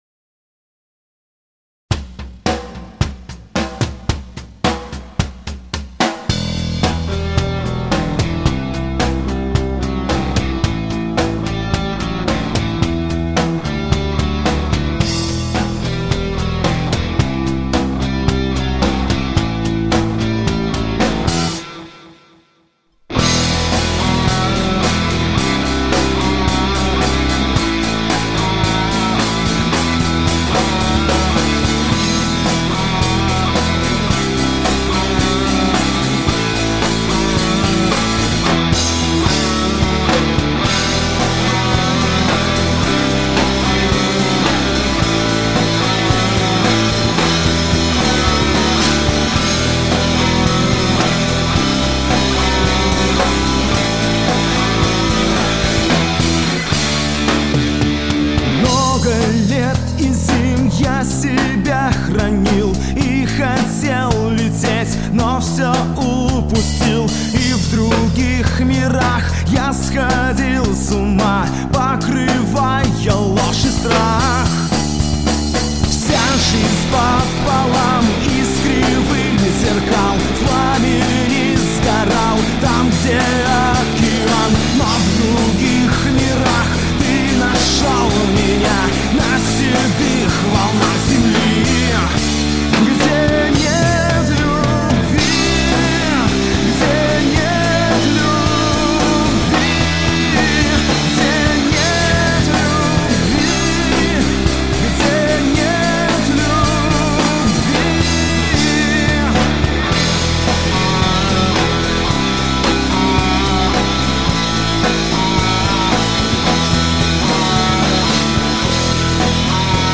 в песне прослушивается современный подход к рок музыке.